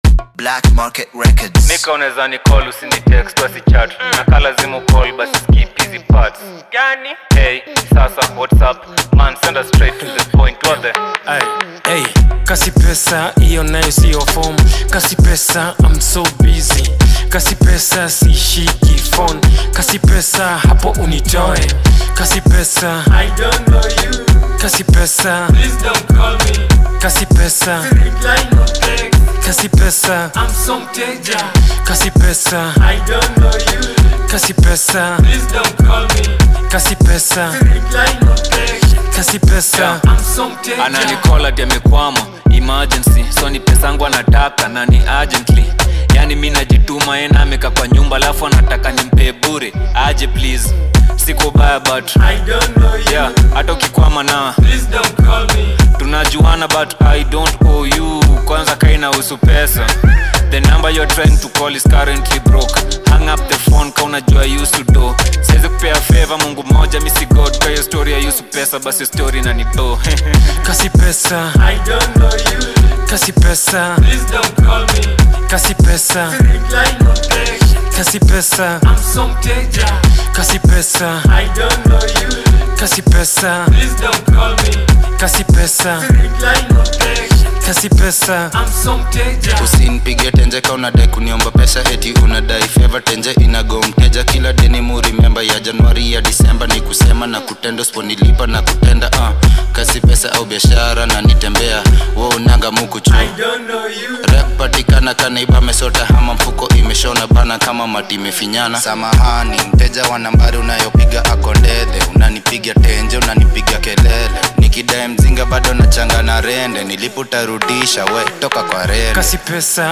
a masterful mix of rhythmic basslines and sharp percussion